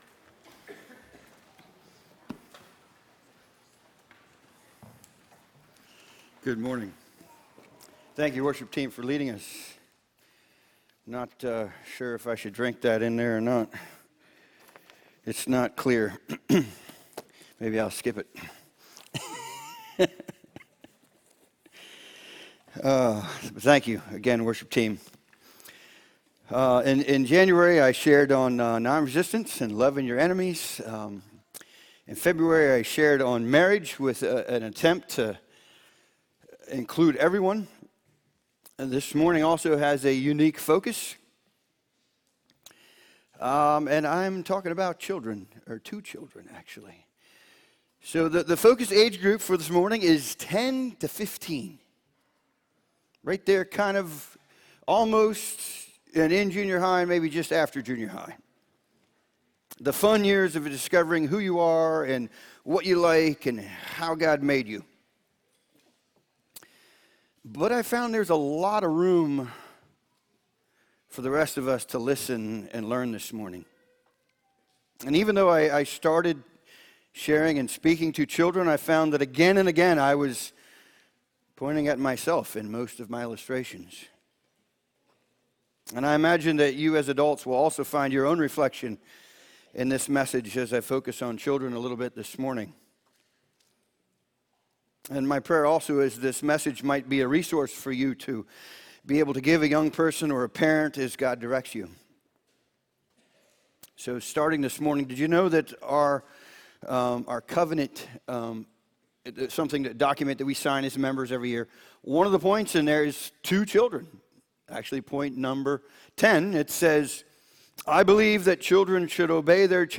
Sermon Archive | - New Covenant Mennonite Fellowship